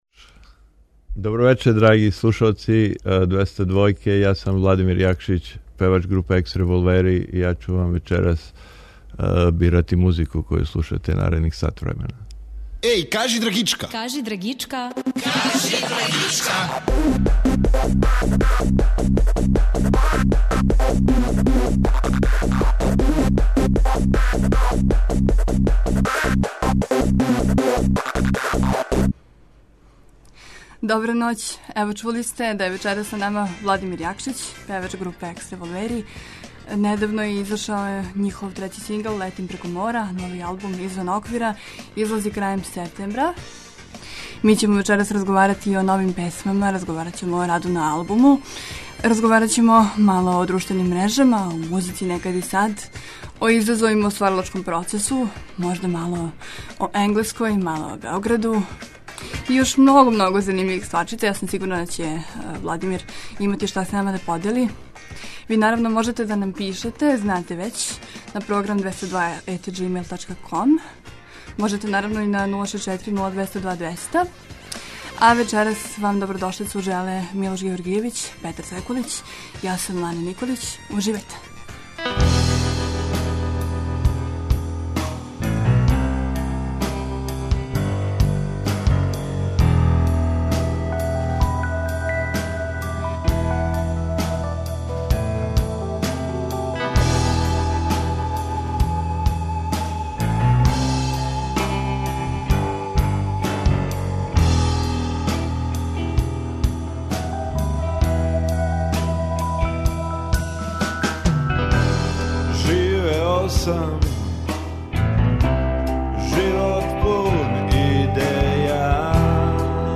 Свако вече, од поноћи на Двестадвојци у емисији Кажи драгичка гост изненађења!